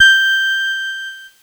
Cheese Note 25-G4.wav